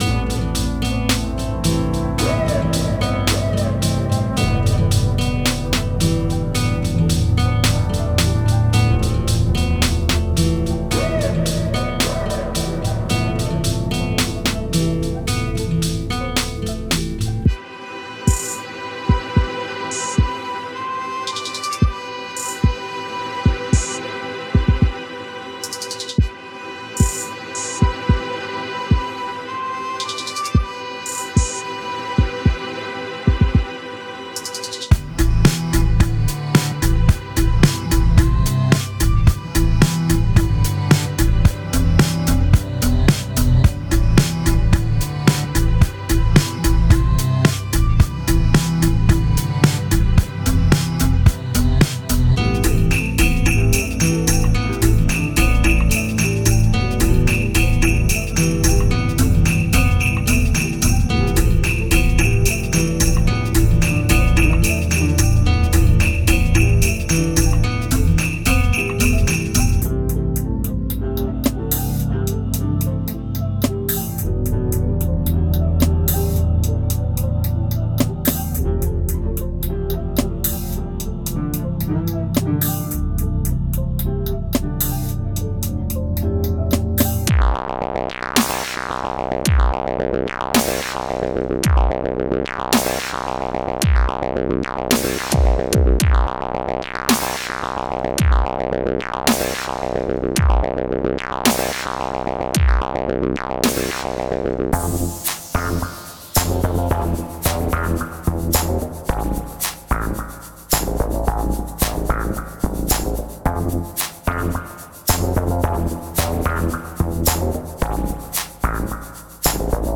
just made this other beat. was up for two days straight and had to make a beat
all you did was use loops, but overall they do match